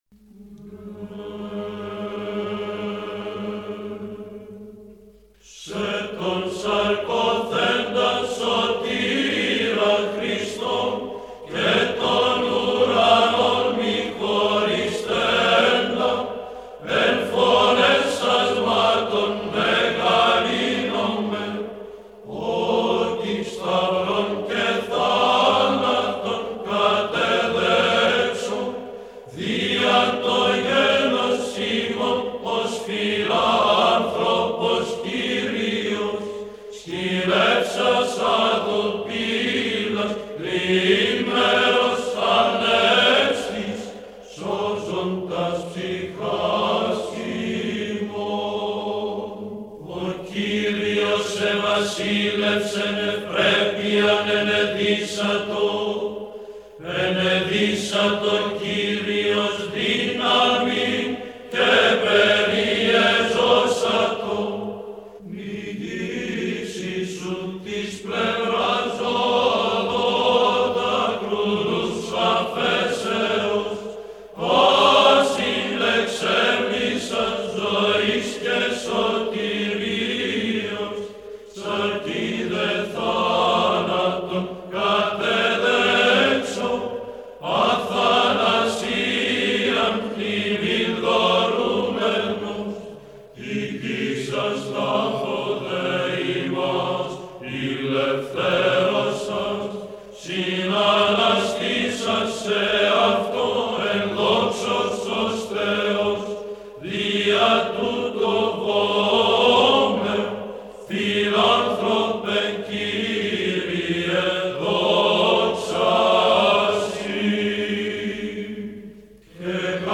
Απόστιχα Πλαγίου του Πρώτου ήχου – χορός Πανελληνίου συνδέσμου Ιεροψαλτών «Ρωμανός ο Μελωδός και Ιωάννης ο Δαμασκηνός».mp3